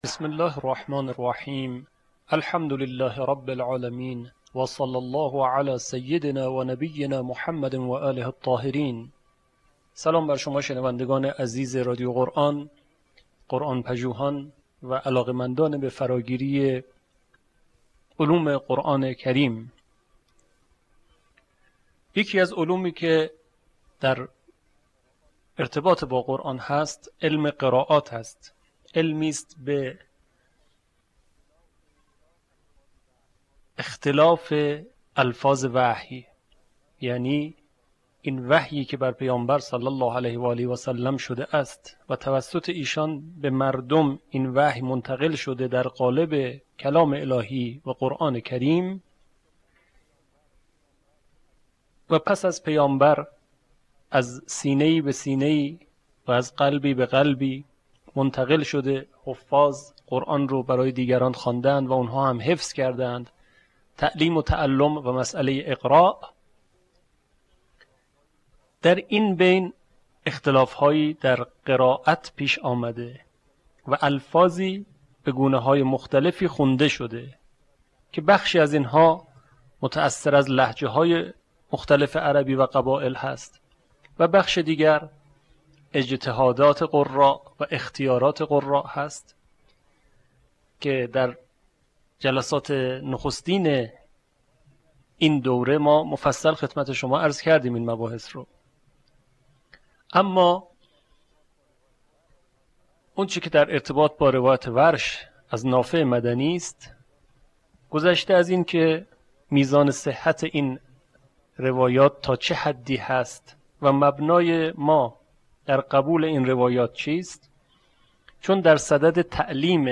صوت | آموزش آیاتی از سوره طه به روایت وَرش
به همین منظور مجموعه آموزشی شنیداری (صوتی) قرآنی را گردآوری و برای علاقه‌مندان بازنشر می‌کند.